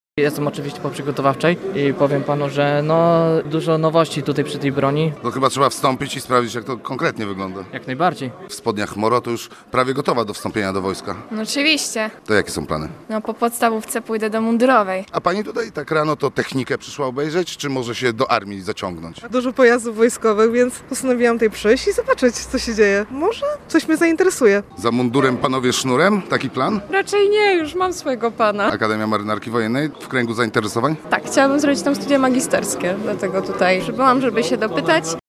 Wojskowe Targi Służby i Pracy. Kto je odwiedza i dlaczego? [SONDA]
W siedzibie Wyższej Szkoły Administracji i Biznesu w Gdyni rozpoczęła się druga edycja Wojskowych Targów Służby i Pracy.